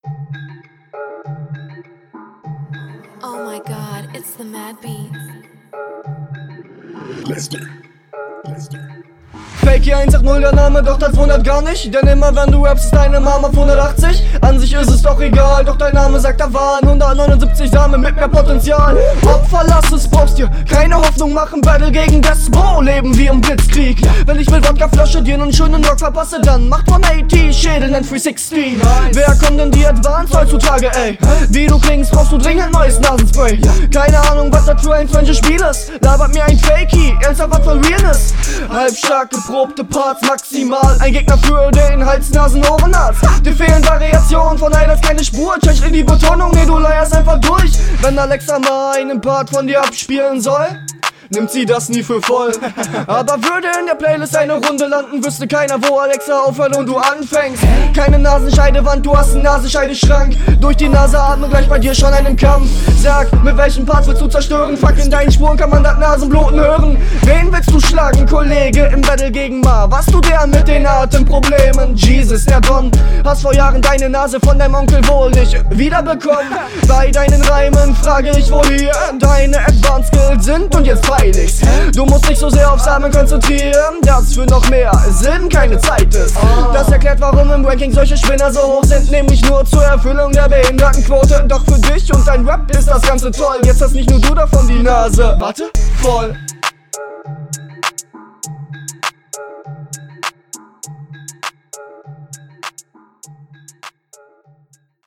Flow: Sehr guter Flow in dieser Runde.
Der Beat passt gut zu deiner Stimme, leider is deine Mische nach wie vor verbeserungswürdig, …